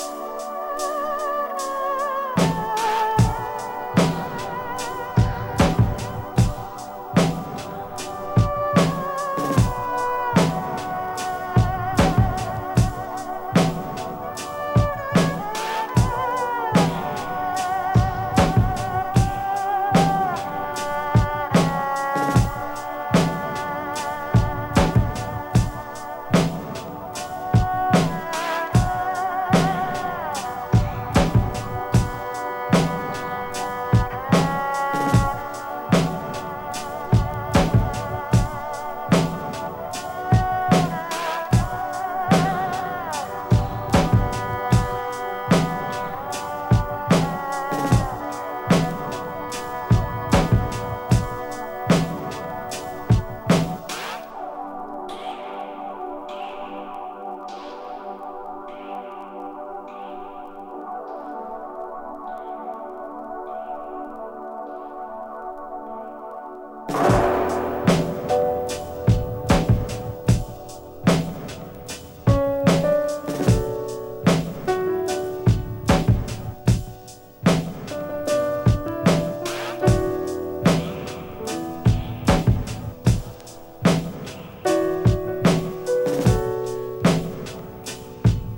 ヘヴィーなドラムに切ないヴァイオリンが絡む